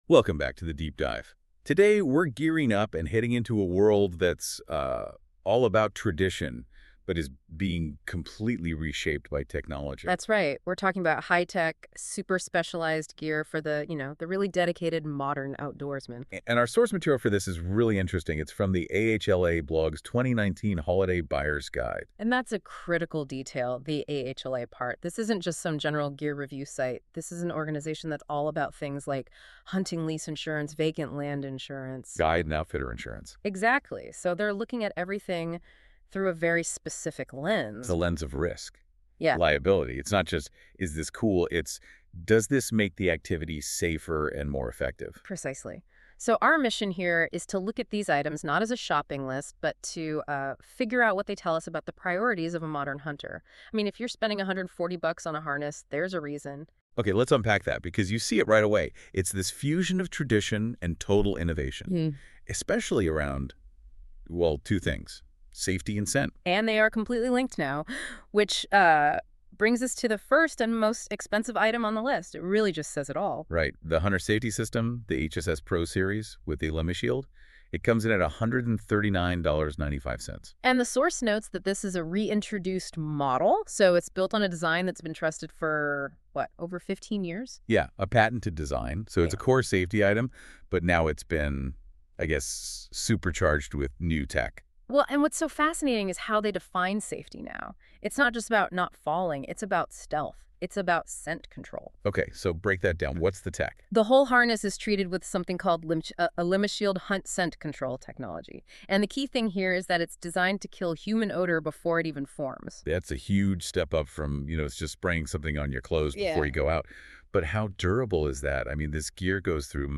AI generated summary This transcript features a deep dive discussion regarding the AHLA 2019 holiday buyer guide, focusing on how modern hunting equipment integrates advanced technology with traditional practices.